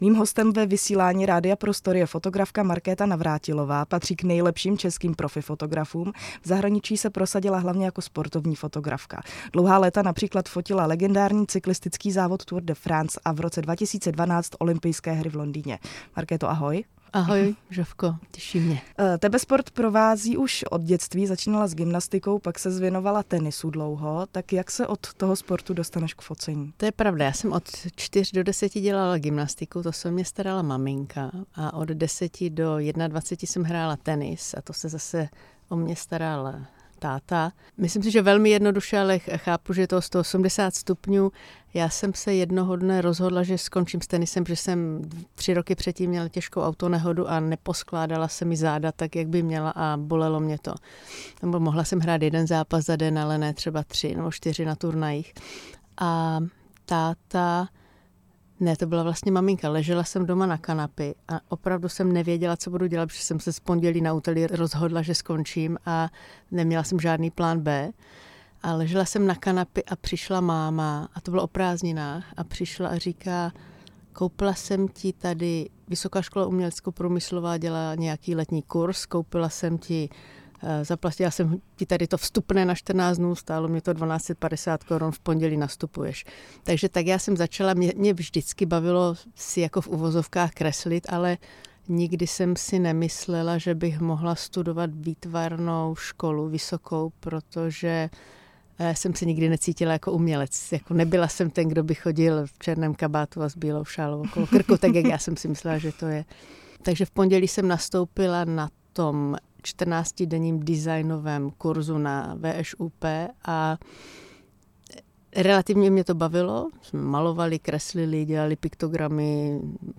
V rozhovoru přibližuje svůj profesní vývoj, zážitky z cest i to, jaké emoce v ní vzbuzuje práce s lidmi.